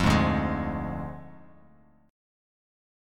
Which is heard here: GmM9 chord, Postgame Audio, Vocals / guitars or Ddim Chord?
Ddim Chord